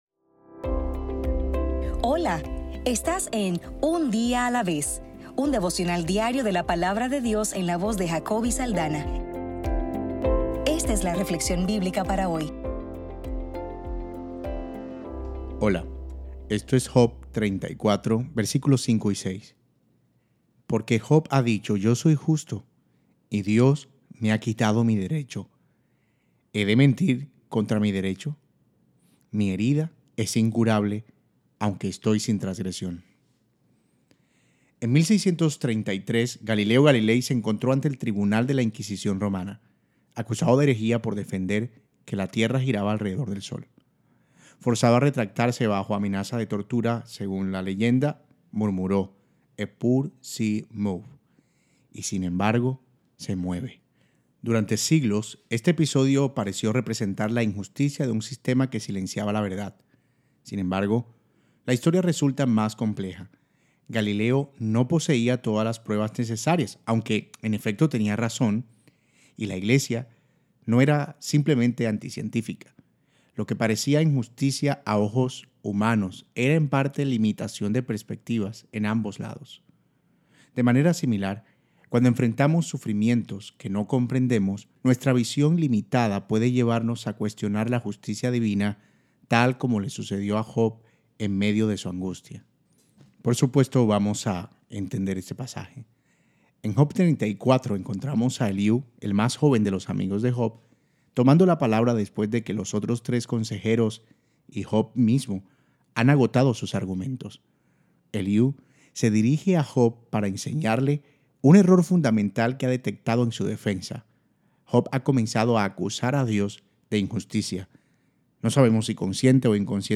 Devocional para el 5 de marzo